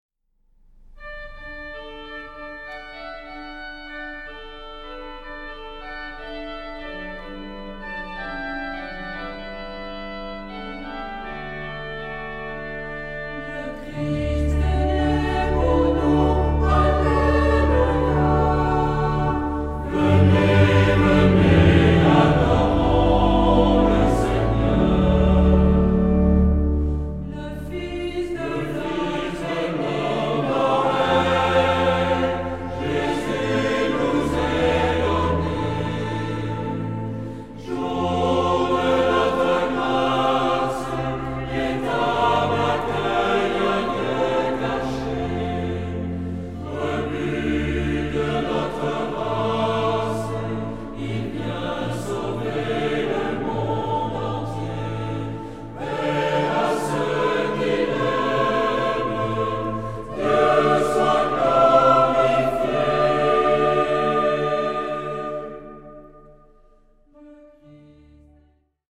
Genre-Style-Forme : Sacré ; noël
Type de choeur : SATB  (4 voix mixtes )
Tonalité : ré mineur ; ré majeur